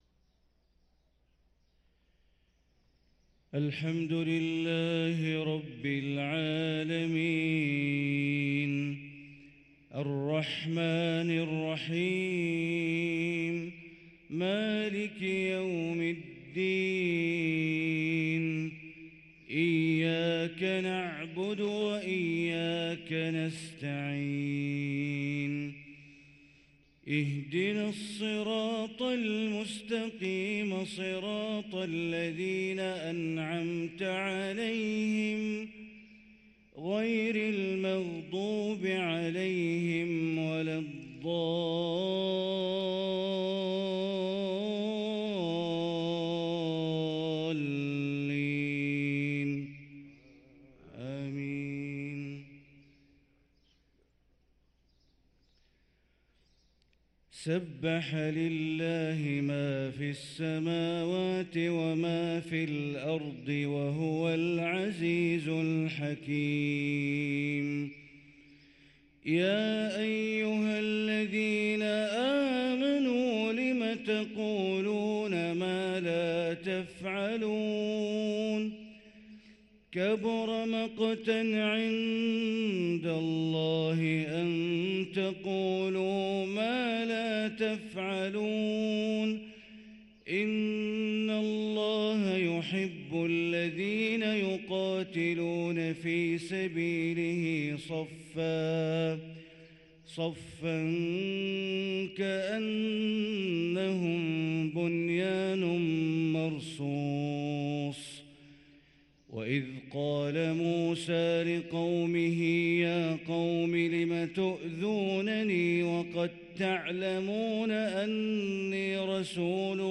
صلاة الفجر للقارئ بندر بليلة 1 رجب 1444 هـ
تِلَاوَات الْحَرَمَيْن .